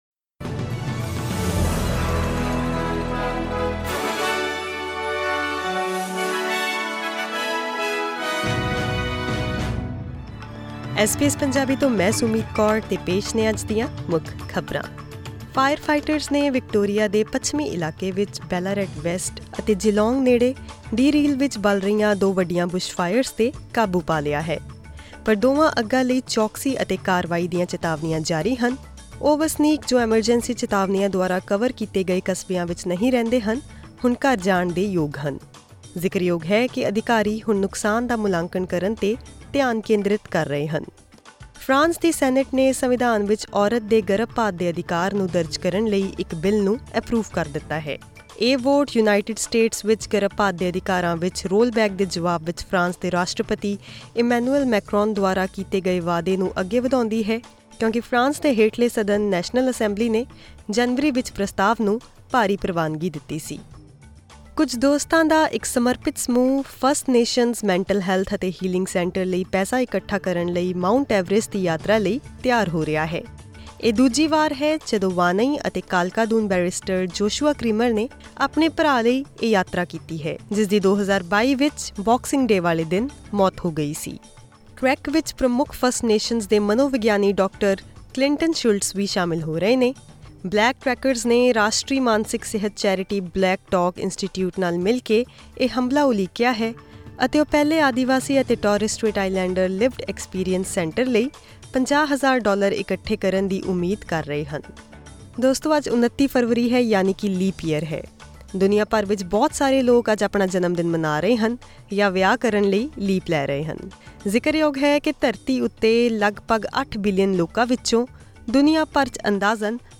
ਐਸ ਬੀ ਐਸ ਪੰਜਾਬੀ ਤੋਂ ਆਸਟ੍ਰੇਲੀਆ ਦੀਆਂ ਮੁੱਖ ਖ਼ਬਰਾਂ: 29 ਫਰਵਰੀ, 2024